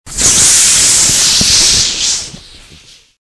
На этой странице собраны звуки космических ракет: от рева двигателей при старте до гула работы систем в открытом космосе.
Звук капсулы: отсоединение во время взлета ракеты